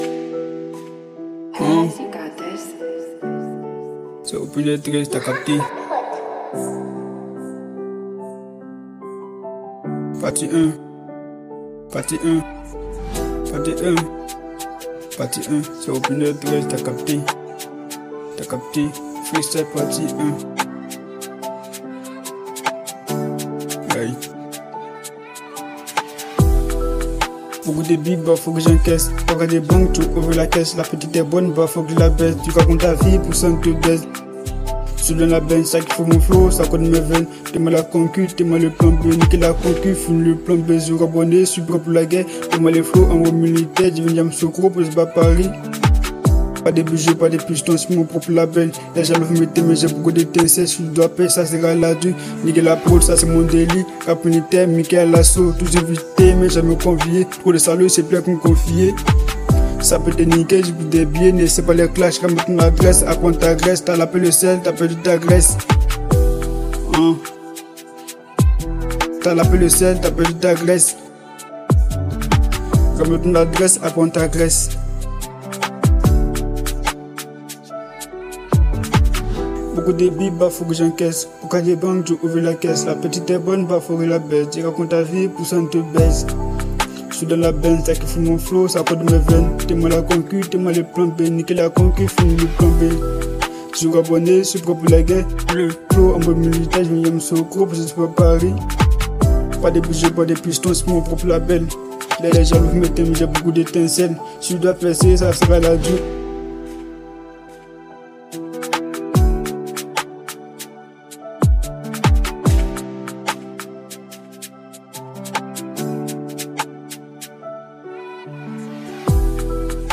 | Afro trap